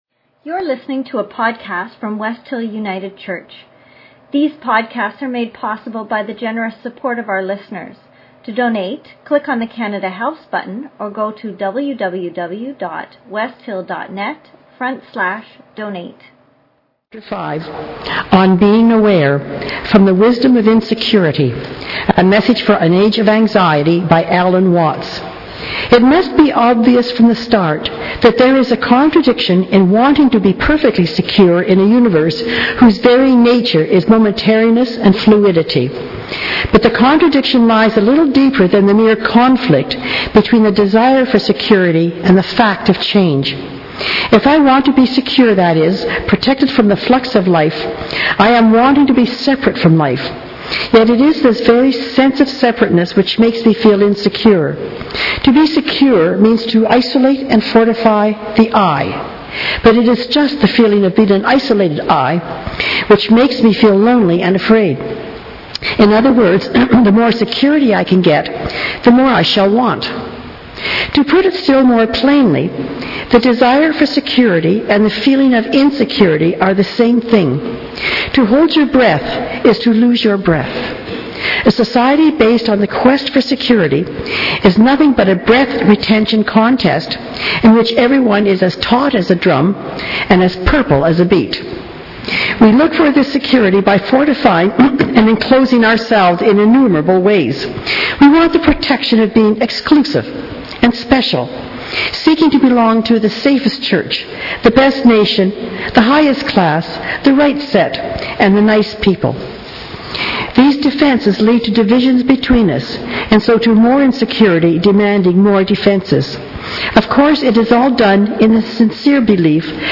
His journey was a fascinating story, and brought up lots of interesting questions for members of the congregation.